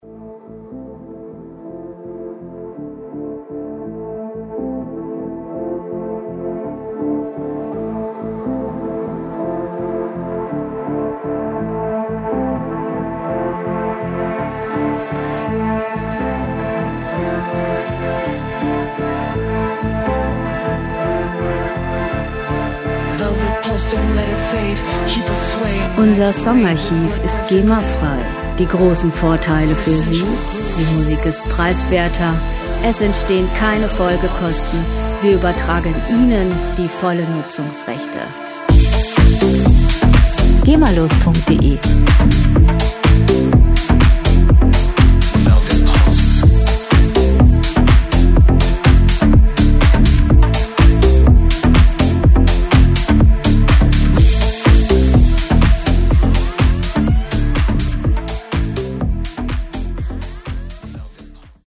Werbemusik - Fashion
Musikstil: Deep House
Tempo: 124 bpm
Tonart: A-Moll
Charakter: lässig, pulsierend
Instrumentierung: Synthesizer, Vocals